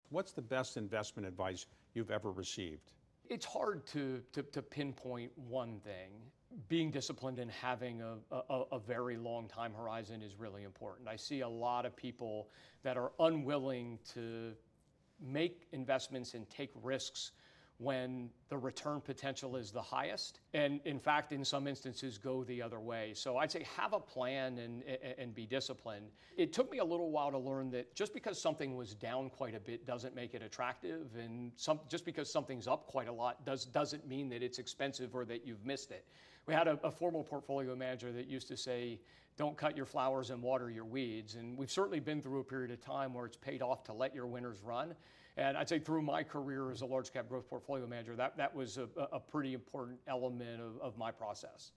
Stay disciplined and have a long-time horizon. He speaks with David Rubenstein on "Bloomberg Wealth."